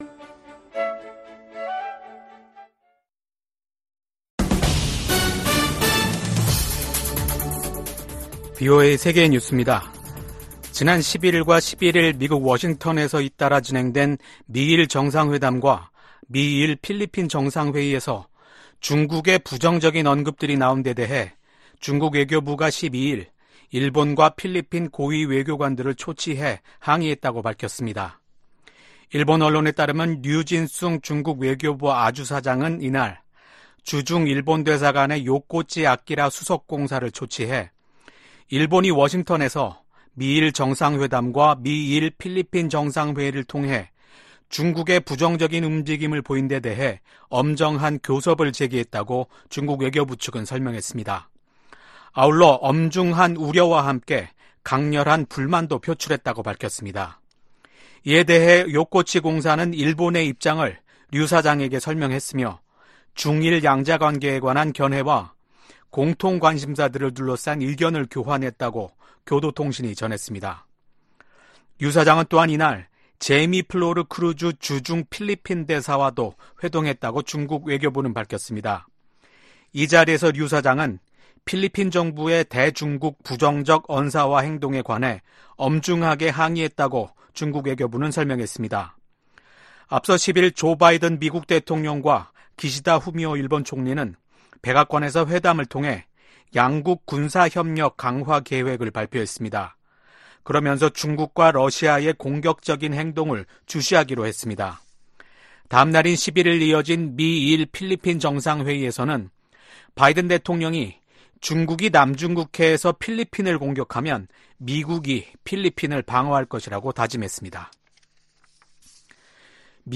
VOA 한국어 아침 뉴스 프로그램 '워싱턴 뉴스 광장' 2024년 4월 13일 방송입니다. 미국과 일본, 필리핀이 11일 워싱턴에서 사상 첫 3자 정상회의를 열고 남중국해와 북한 문제 등 역내 현안을 논의했습니다. 기시다 후미오 일본 총리는 미국 의회 상∙하원 합동회의 연설에서 현재 전 세계적으로 위협받고 있는 자유와 민주주의를 수호하려는 미국의 노력에 일본이 함께하겠다고 말했습니다.